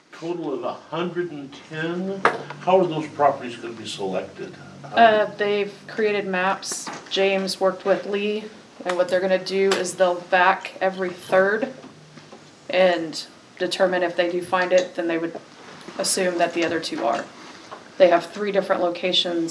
During the May 5th Vandalia City Council meeting, the plan to work to identify any lead service lines was presented to the City Council.  Alderman Bret Brosman asked City Administrator LaTisha Paslay for some further information on the work.